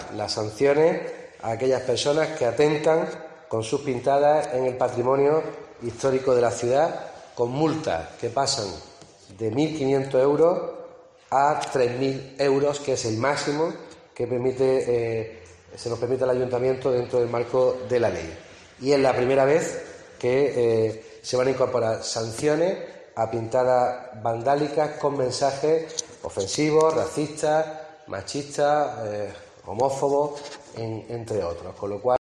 Jacobo Calvo, concejal de medioambiente